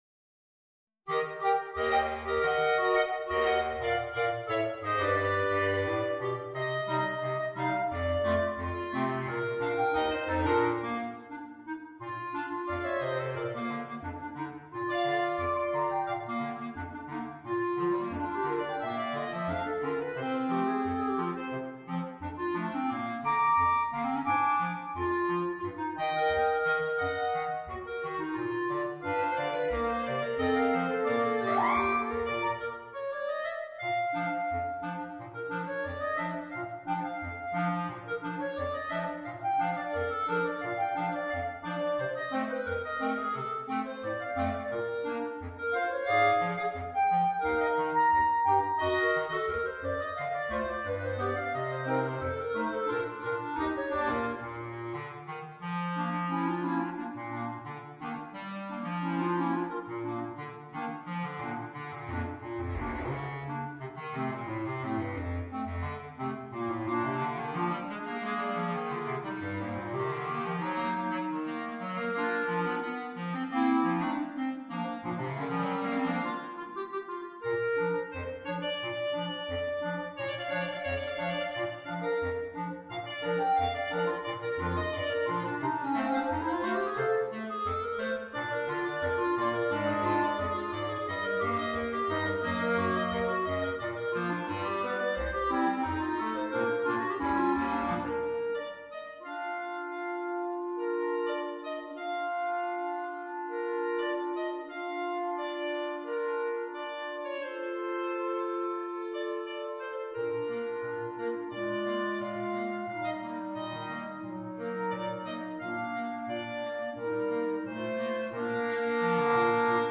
for clarinet quartet